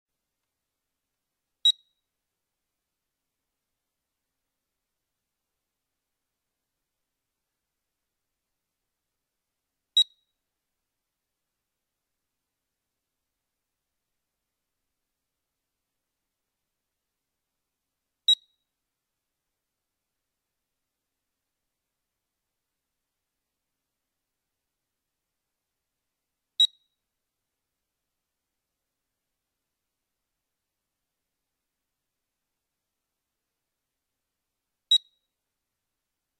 「ピッ、ピッ、ピッ……」。こんな音を自宅で耳にしたことがある人はいませんか？
…実はこの音、住宅用火災警報器（火災報知器）の電池切れの警告音なのです！
住宅火災警報器は、電池が切れそうになると数十秒に1度警告音を発し、1週間ほどで鳴りやんで動作を停止します。